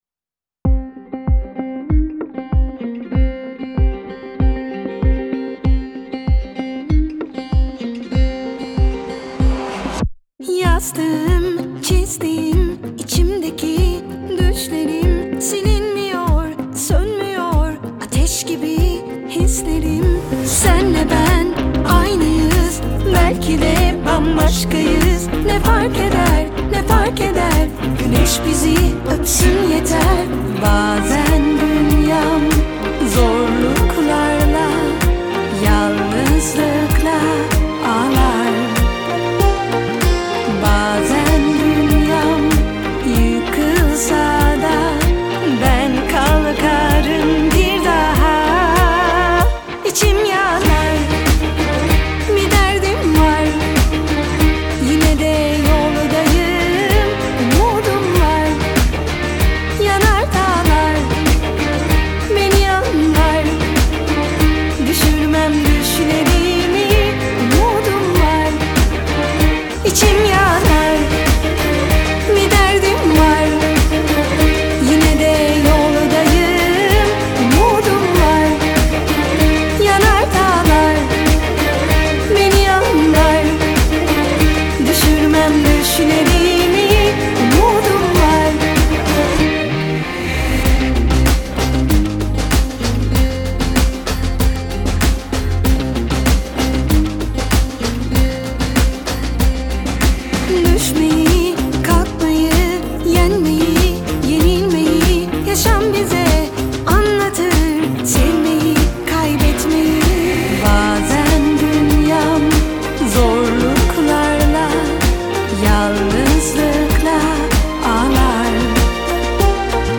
mutlu rahatlatıcı enerjik şarkı.